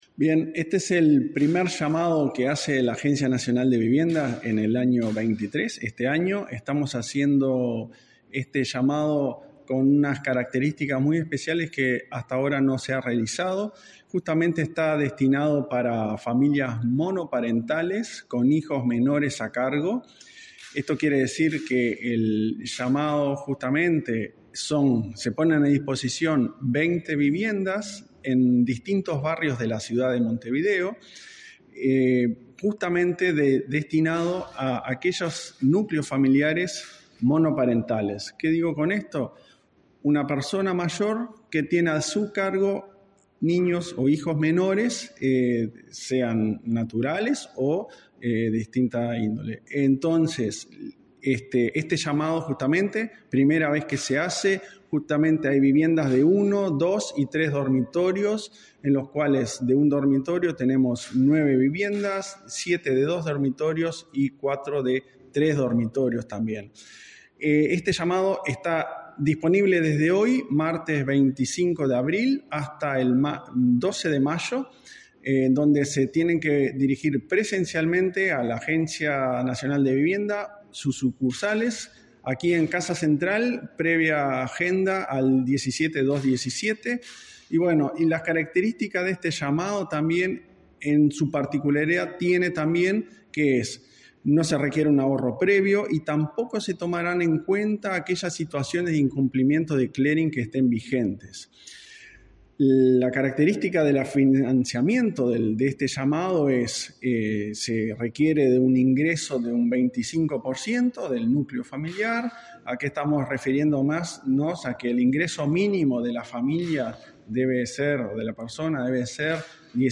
Entrevista al presidente de la ANV, Klaus Mill
En declaraciones a Comunicación Presidencial, este 25 de abril, el presidente del organismo, Klaus Mill, explicó el alcance de la convocatoria.